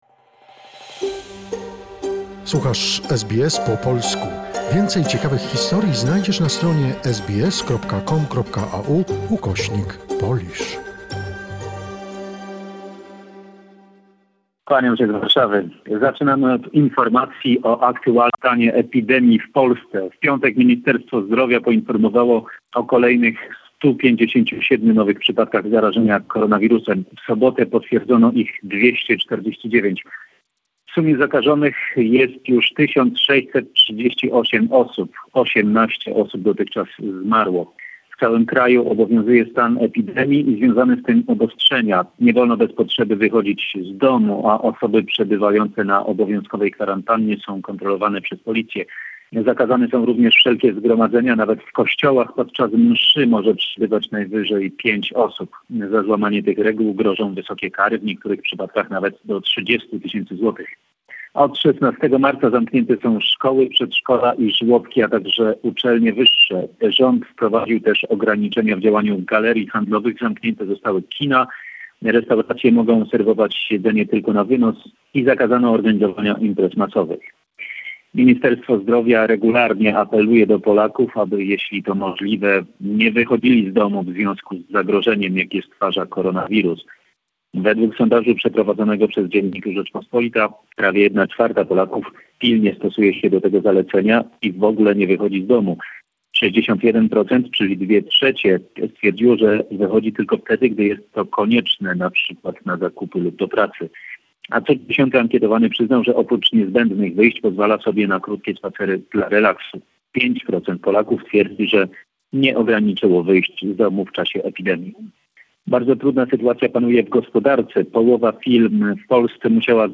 in his weekly report from Poland